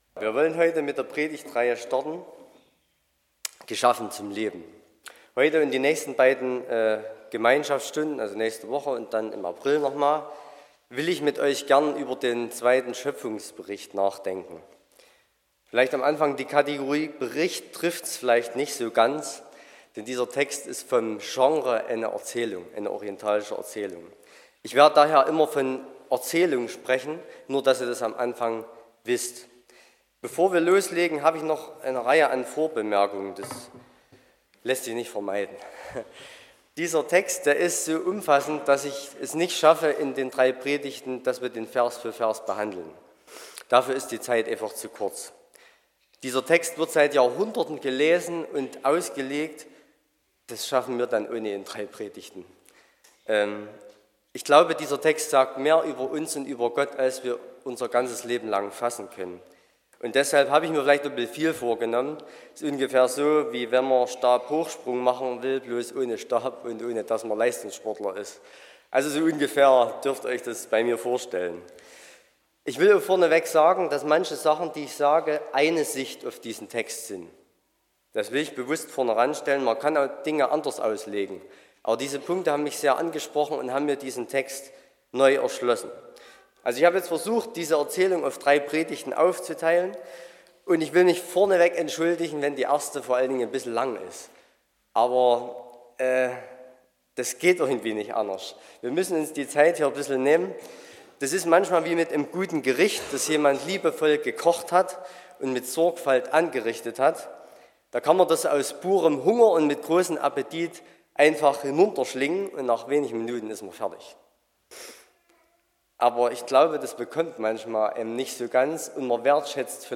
Predigt (Audio): 2024-03-10_Themenreihe__Geschaffen_zum_Leben__Teil_1.mp3 (44,7 MB)